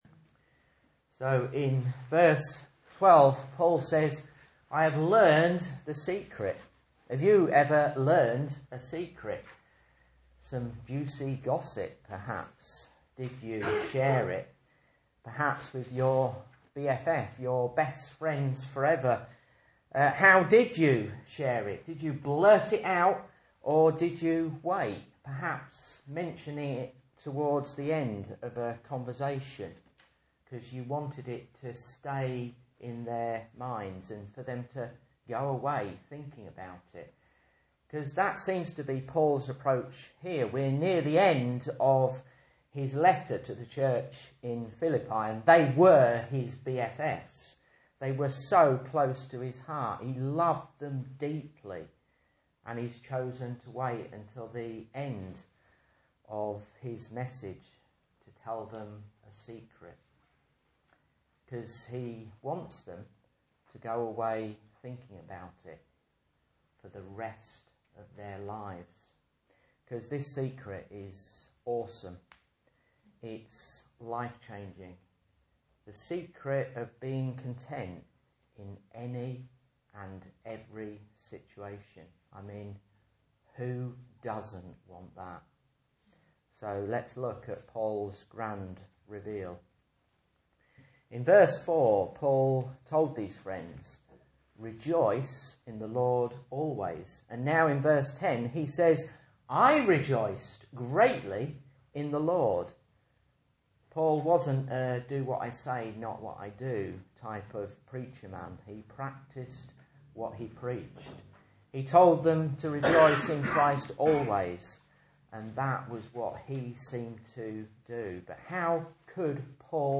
Message Scripture: Mark 4:10-14 | Listen